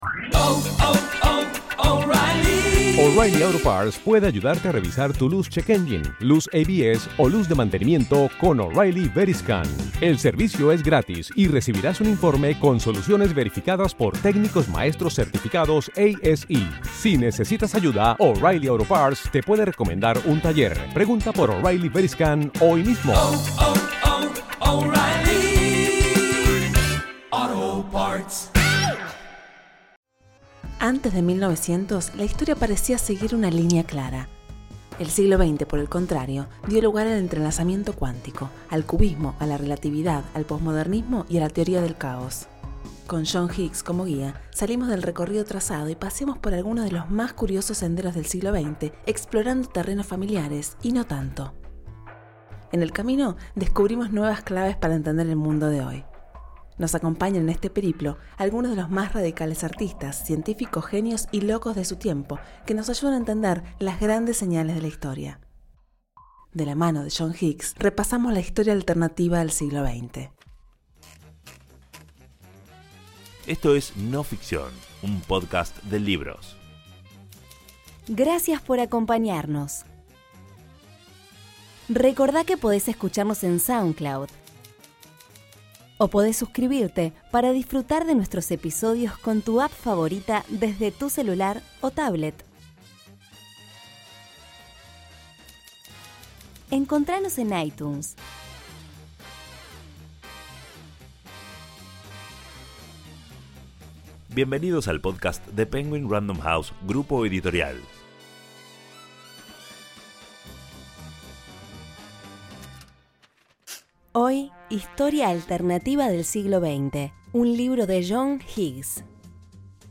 Locución
Actuación
Narradora